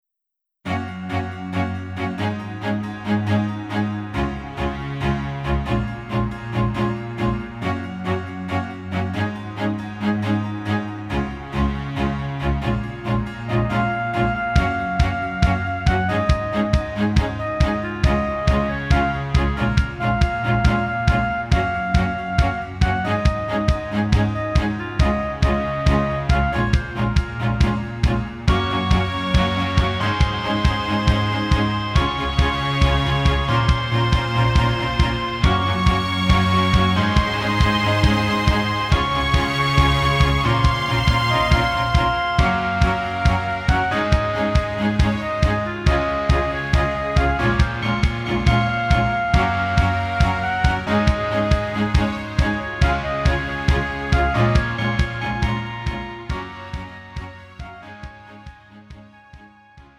음정 여자키
장르 pop 구분 Pro MR